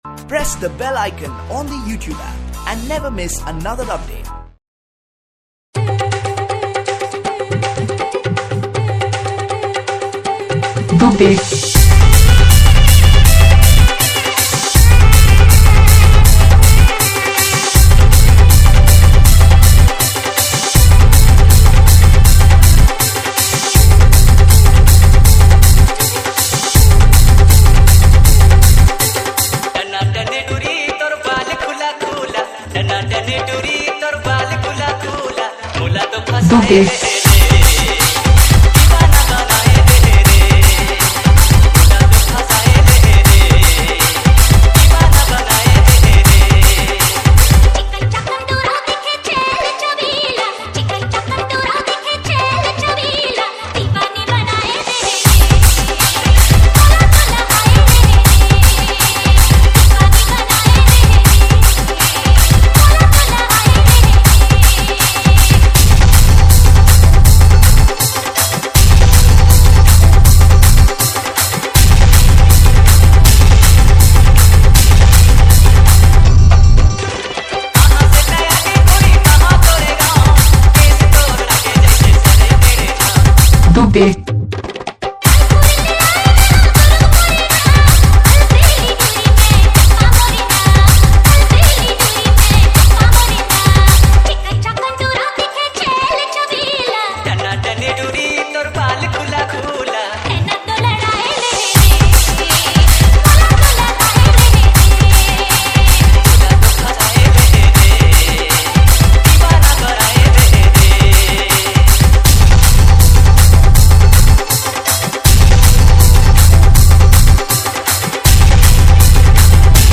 NAGPURI DJ REMIX